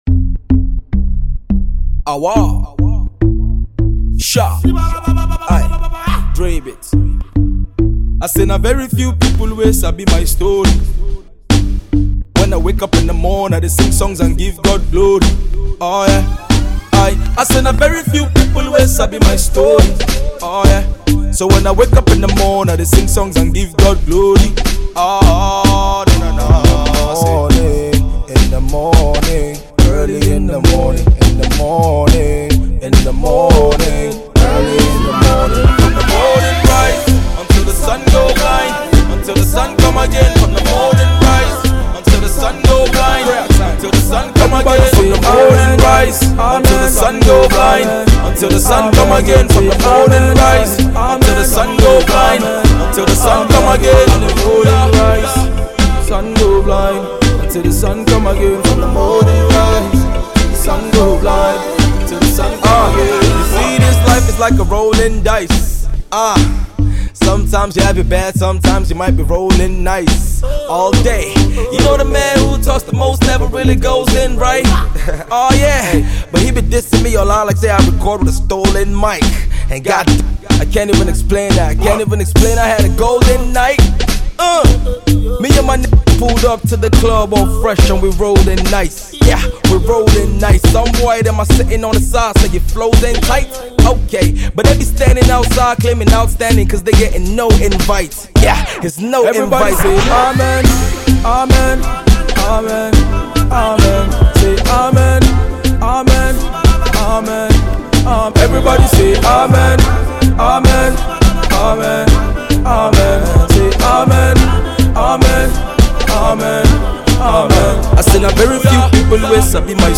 a groovy song